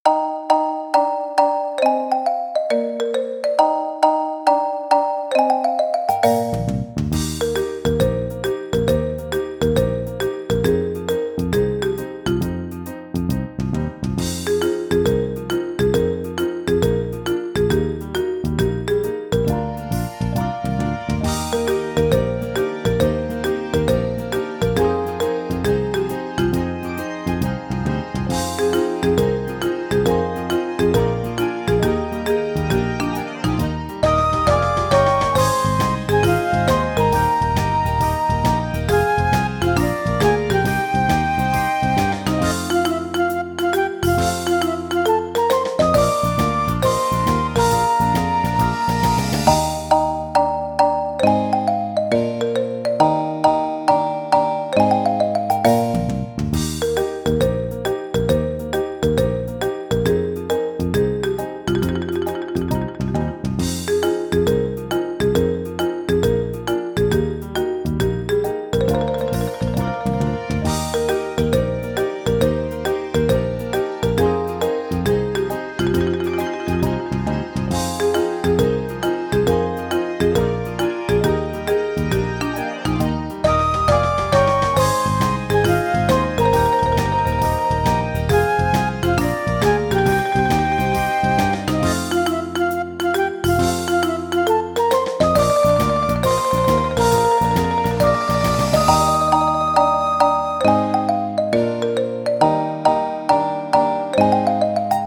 ogg(L) ほがらか ポップ 軽やか